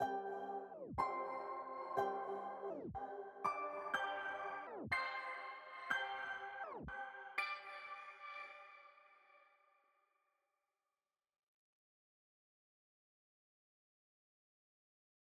Boomin-Beat-Starter-5_Steel Drum.wav